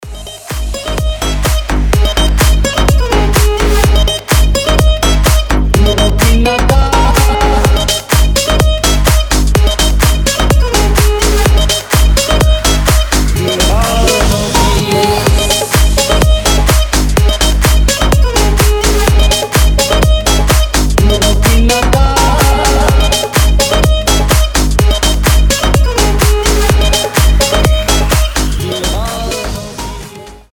• Качество: 320, Stereo
гитара
Club House
быстрые
индийские
ремиксы
Полный энергии рингтон со струнным индийским мотивом